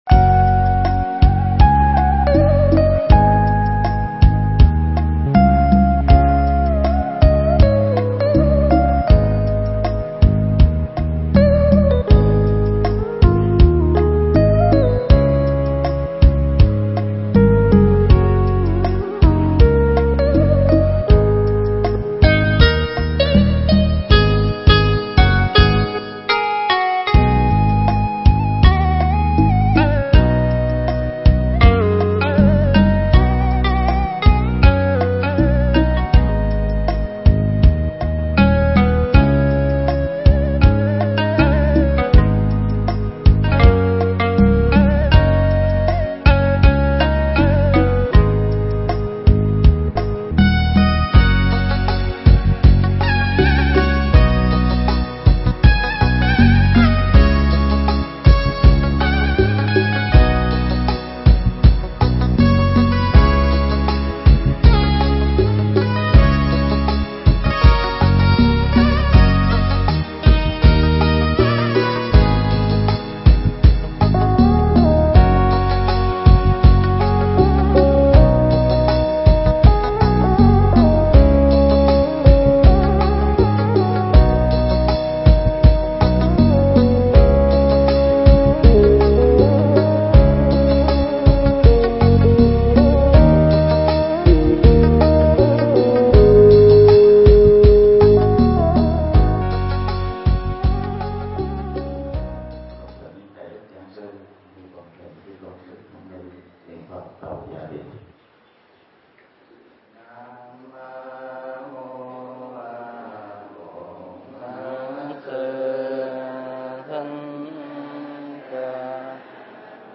Mp3 Thuyết Giảng Trụ Trì Với Việc Quản Trị